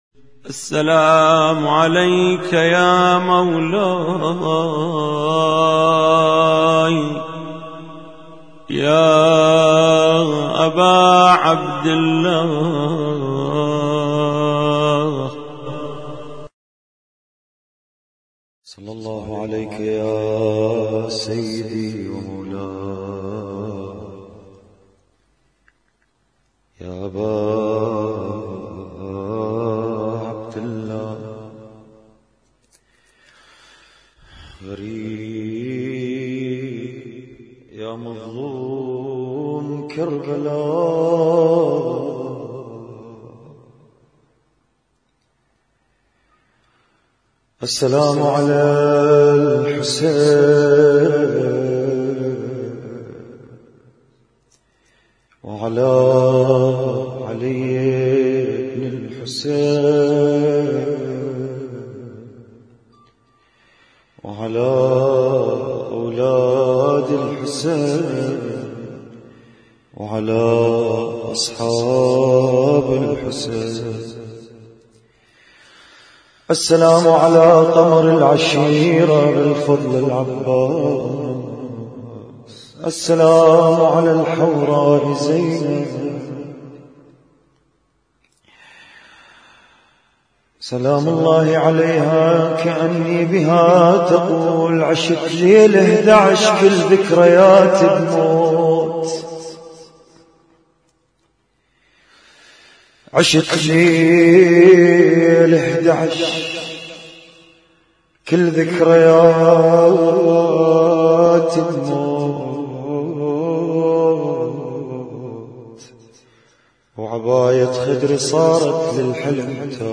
نعي ولطميه
اسم التصنيف: المـكتبة الصــوتيه >> الصوتيات المتنوعة >> النواعي